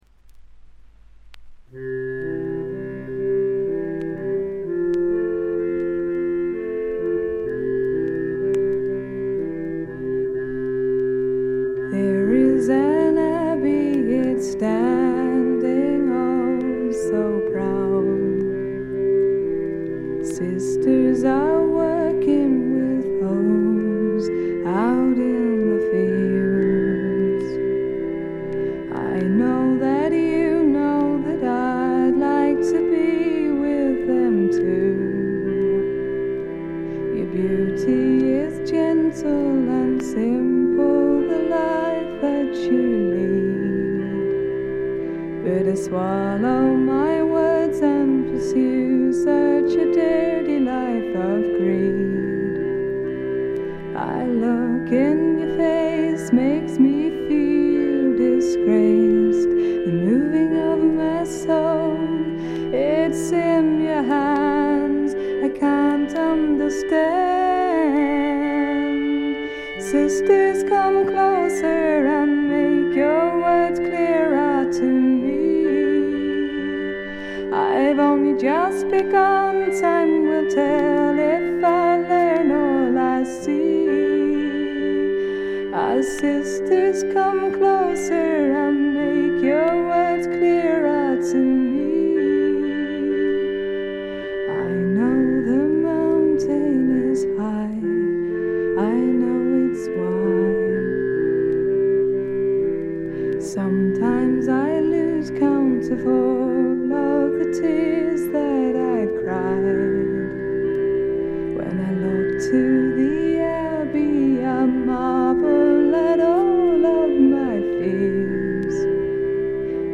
B2冒頭軽いチリプチ。
試聴曲は現品からの取り込み音源です。